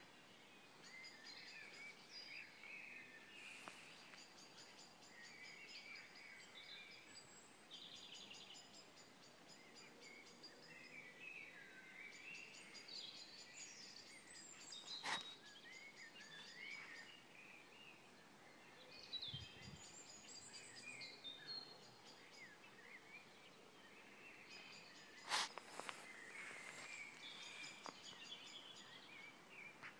Sounds of the lane nearby